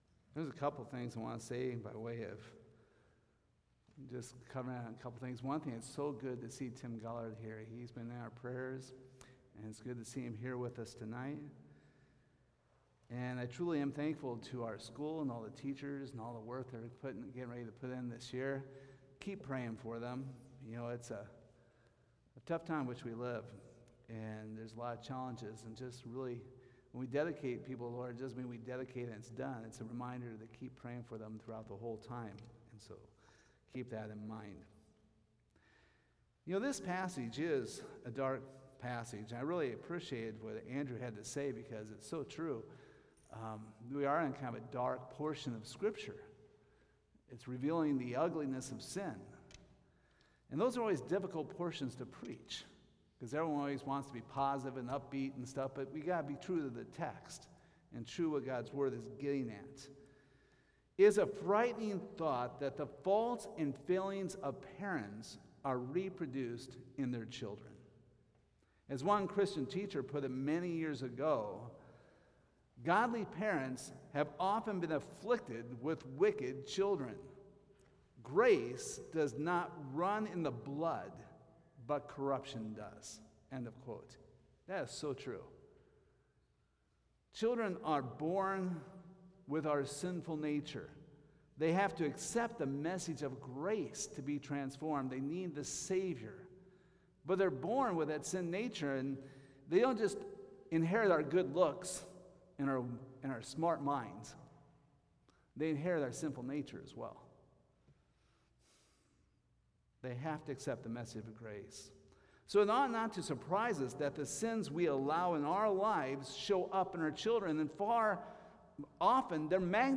2 Samuel 13:1-22 Service Type: Sunday Evening This is a passage no Pastor likes to preach.